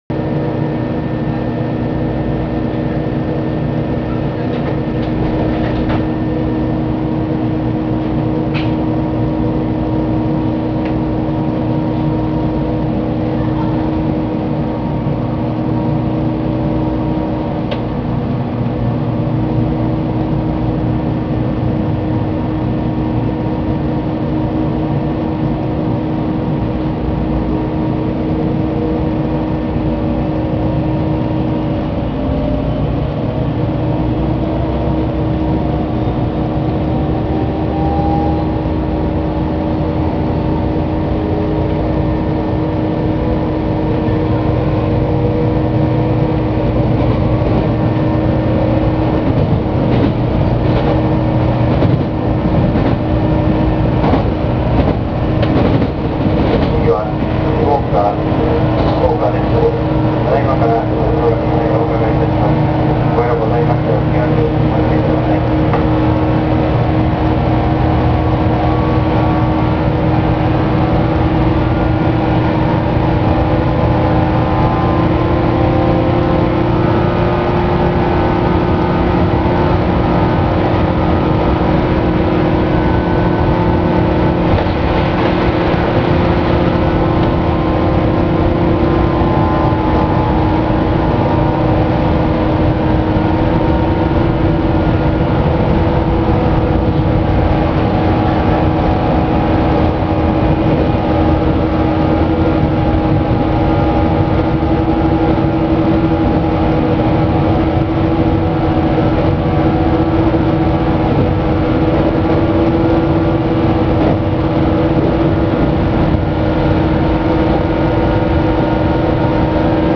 〜車両の音〜
・475系走行音
【北陸本線】石動→福岡（5分24秒：1.71MB）
今時珍しくなった急行型列車の汎用的な走行音。
夏場の収録なのでクーラーの音がうるさいのはご容赦を。